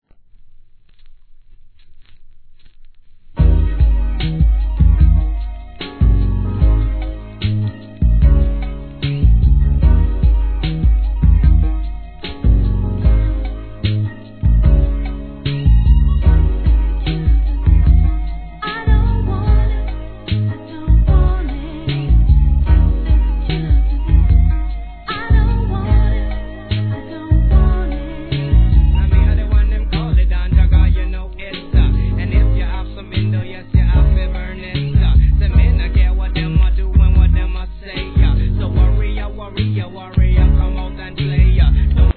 G-RAP/WEST COAST/SOUTH
哀愁漂うトラックでのラガスタイル!